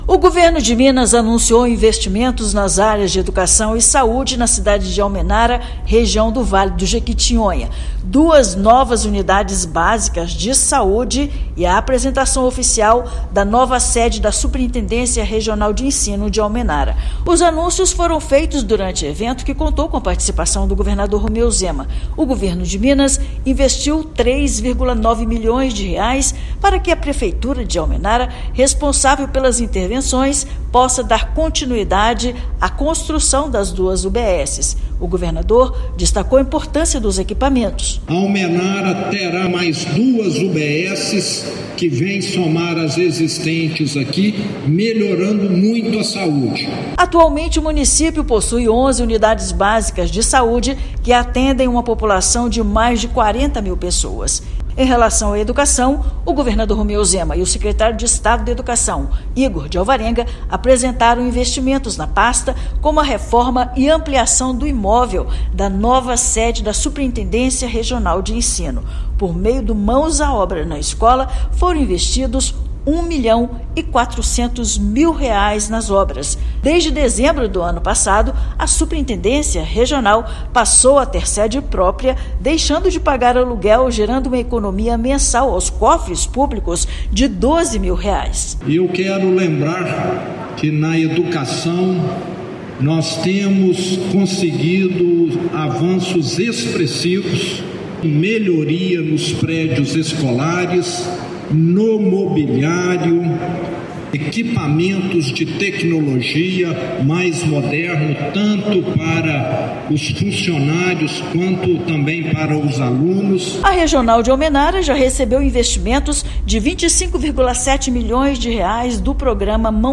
Recursos viabilizam novas unidades básicas de saúde e melhorias nas escolas de toda a região. Ouça matéria de rádio.